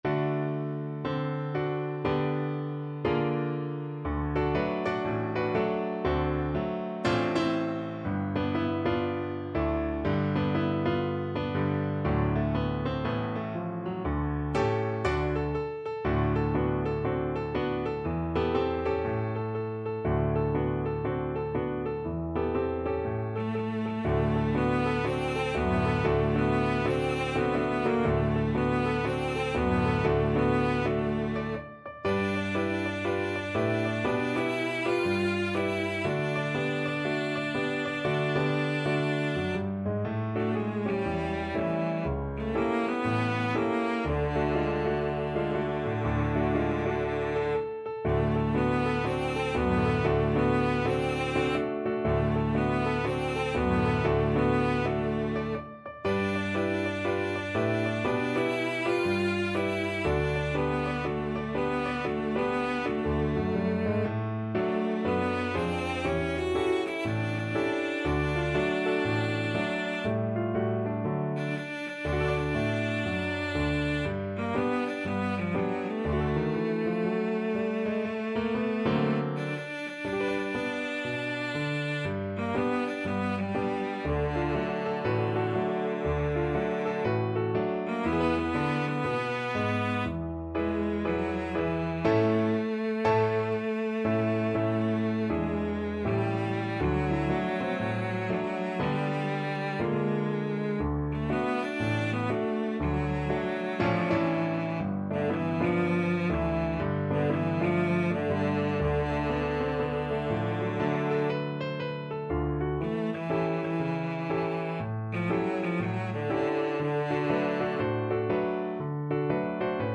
Pop W C Handy Yellow Dog Blues Cello version
Cello
D major (Sounding Pitch) (View more D major Music for Cello )
2/4 (View more 2/4 Music)
Jazz (View more Jazz Cello Music)